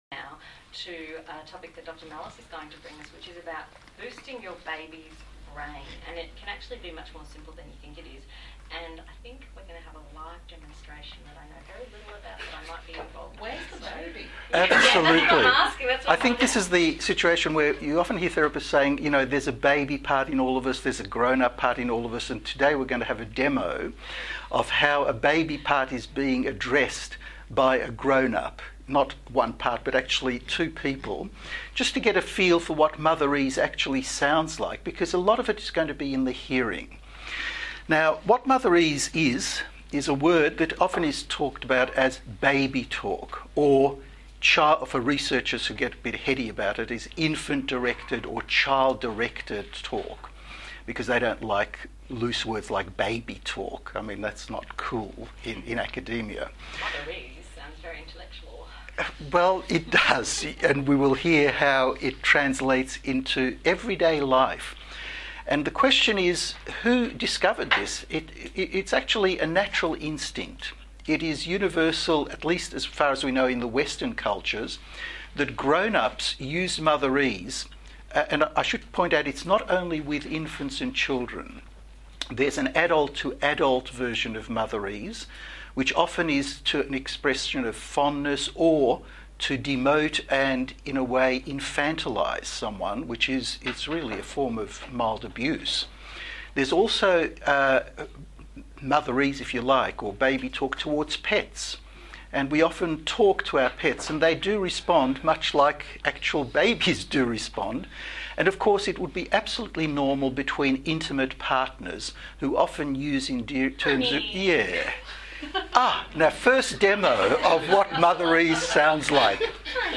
Discussion on the Radiotherapy program on RRR 102.7 FM, Sunday 29th October, 2017.